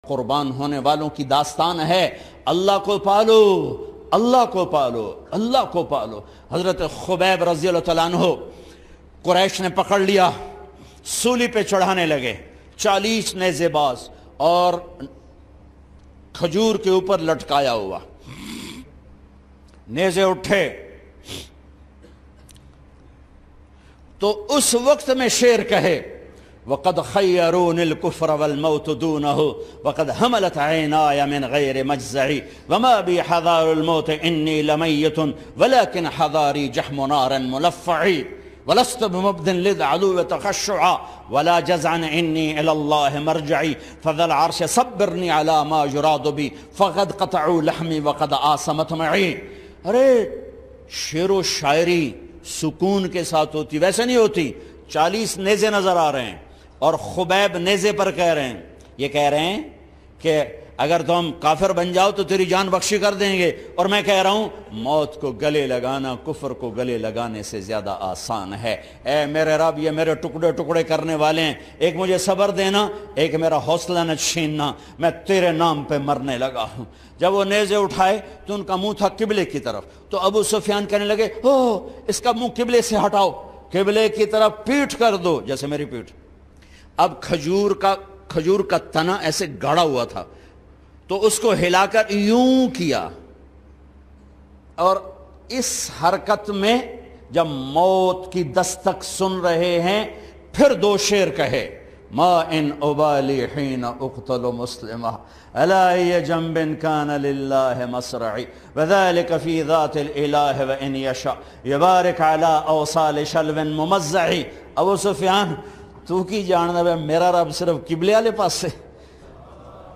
Emotional Hazrat Khubaib R.A ki Shahadat by Maulana Tariq Jameel 2016.mp3